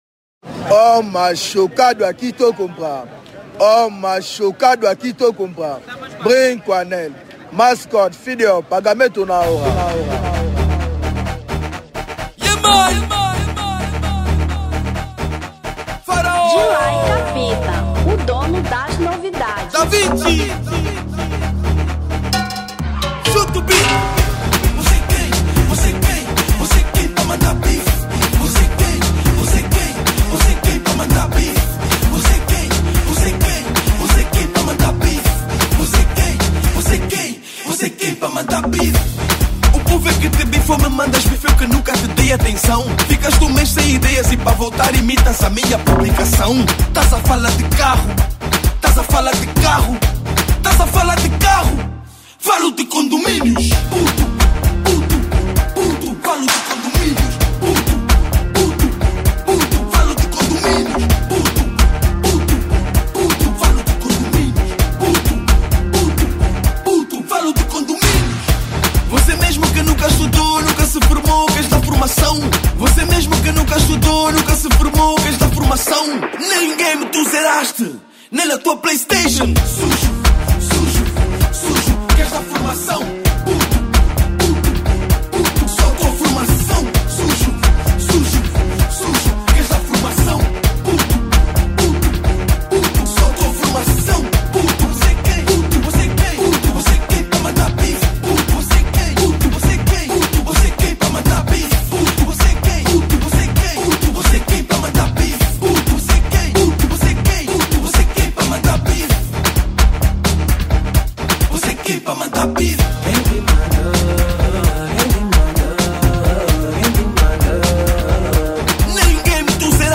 Afro Trap 2025